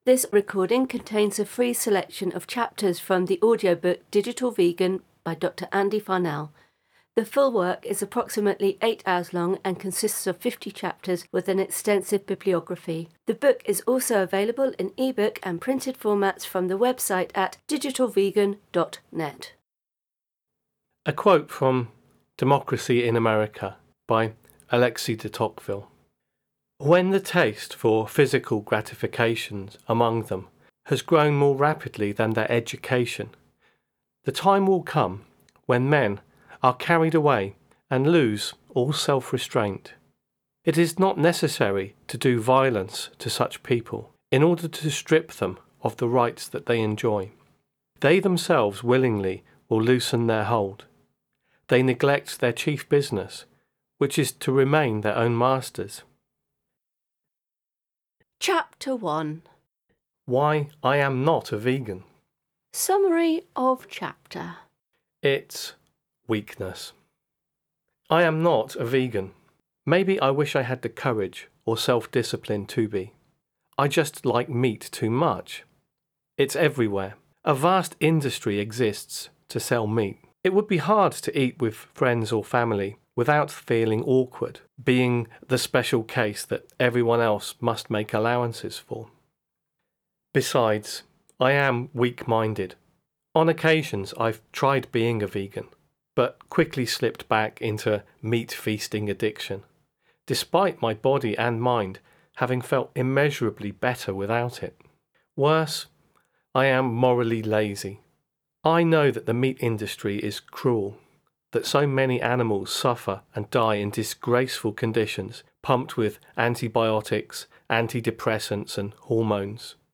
Read by author.